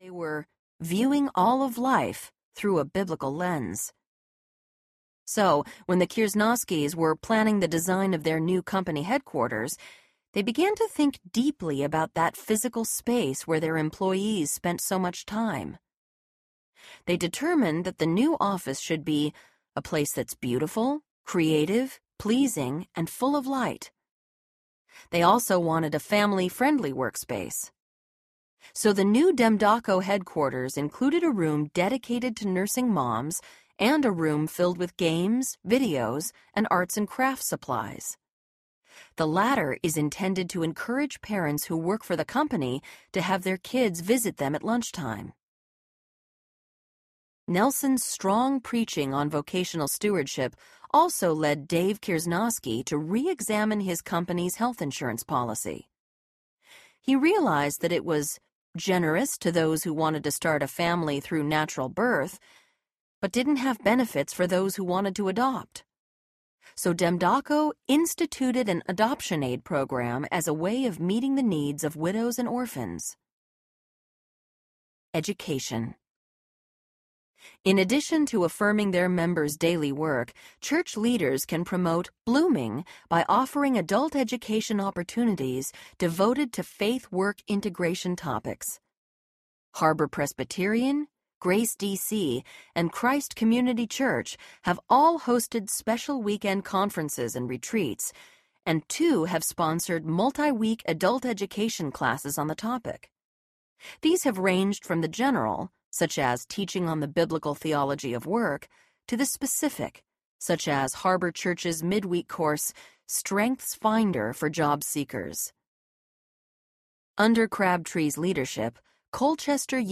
Kingdom Calling Audiobook
10 Hrs. – Unabridged